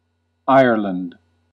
Ireland (/ˈaɪərlənd/
En-us-Ireland.ogg.mp3